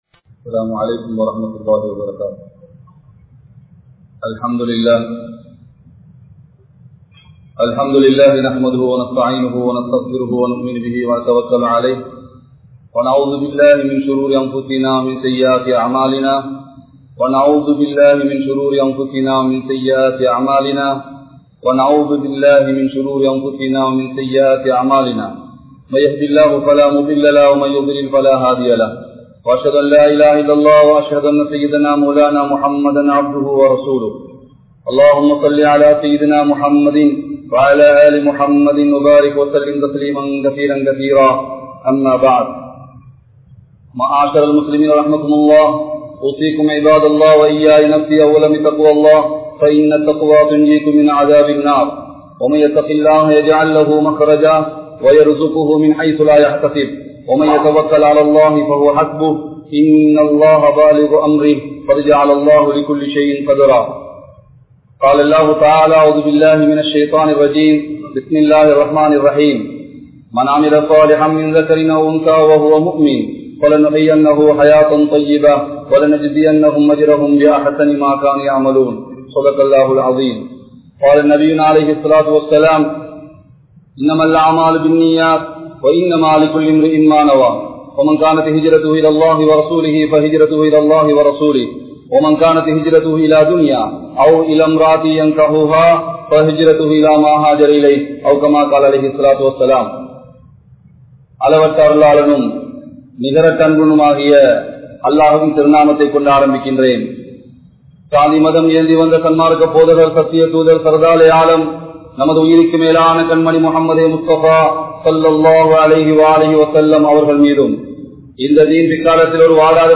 Qualities Of a Mumeen | Audio Bayans | All Ceylon Muslim Youth Community | Addalaichenai